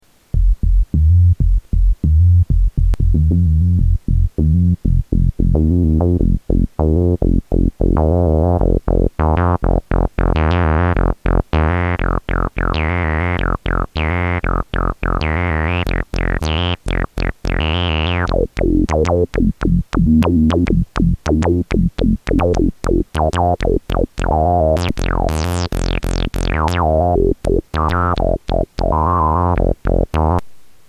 Category: Sound FX   Right: Personal
Tags: Sound effects Moog Little Phatty Little Phatty Moog Synth Sounds